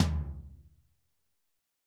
TOM P C L0YR.wav